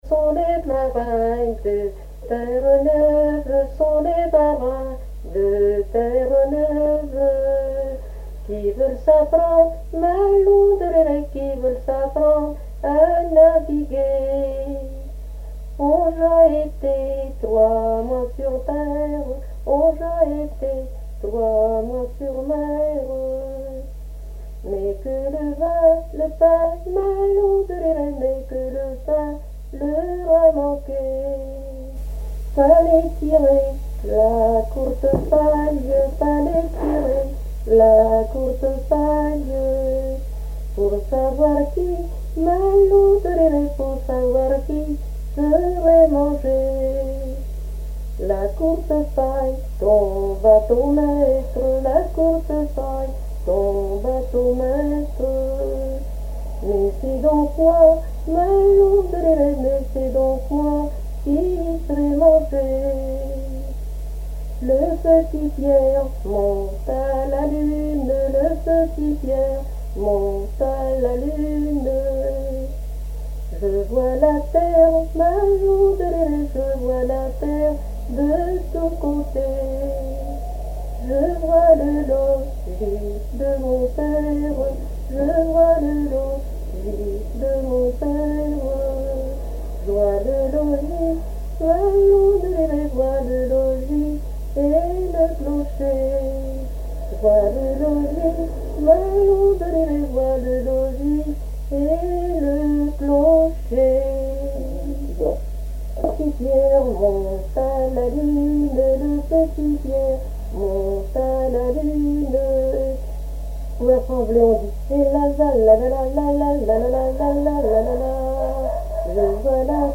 Genre laisse
répertoire de chansons
Pièce musicale inédite